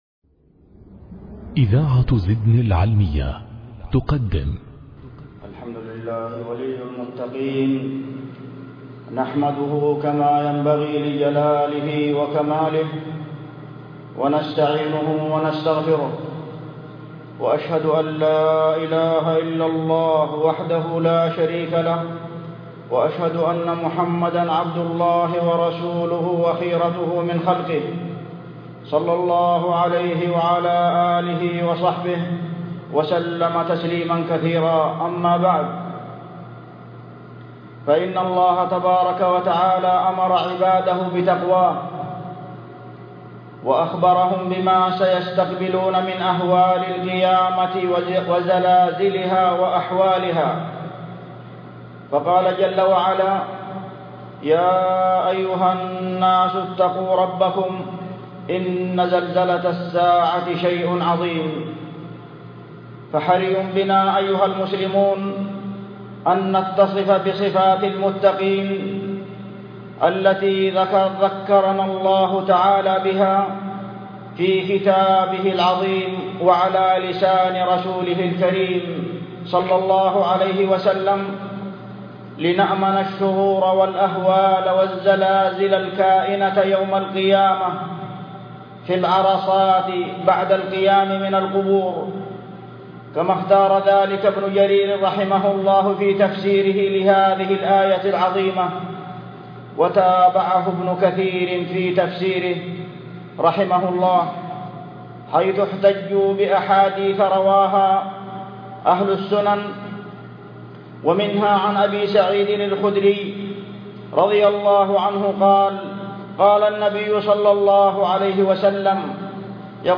مواعظ ورقائق